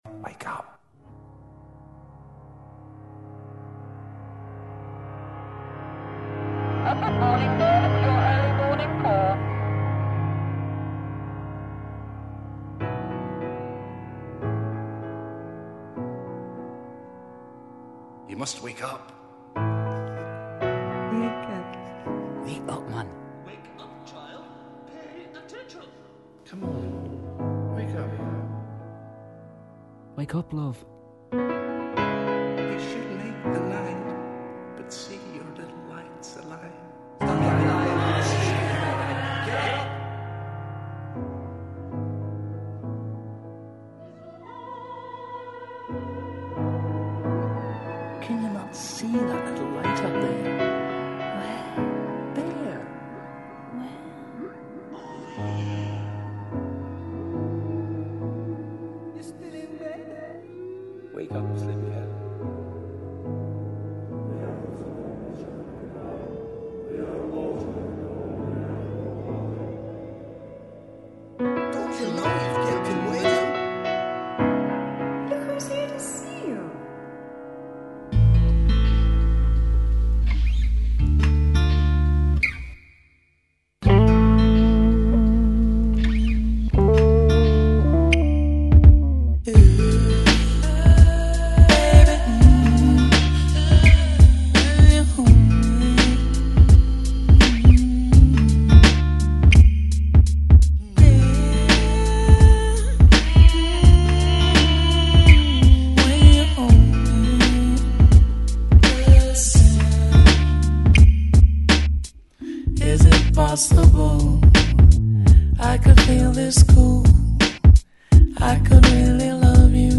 mix